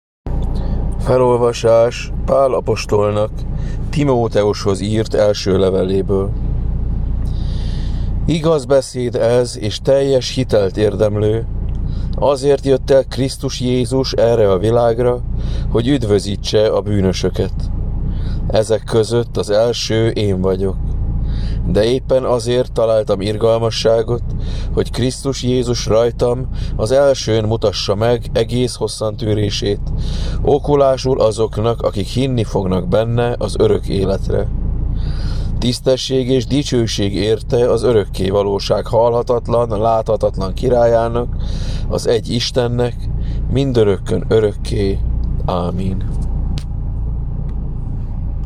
Apostoli olvasmány: